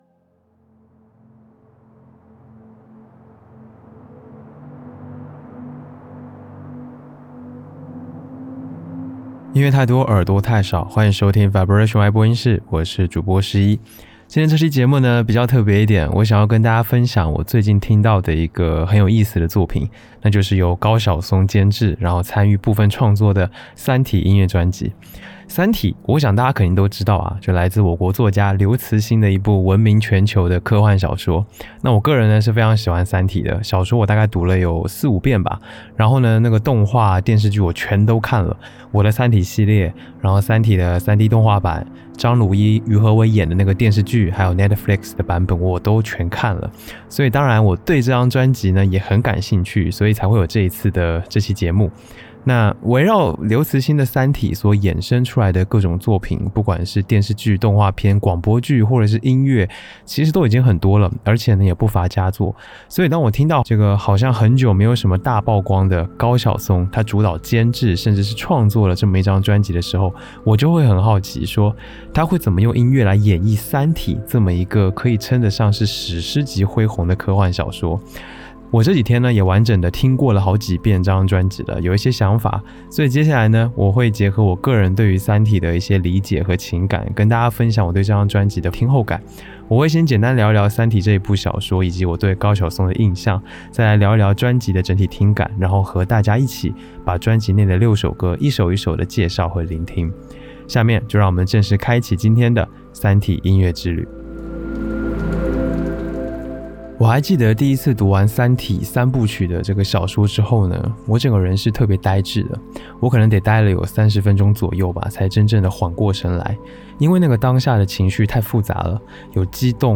「Vibration 歪波音室」是一档以爱好者的视角分享音乐的播客节目，希望能让你进入更丰富有趣的音乐世界。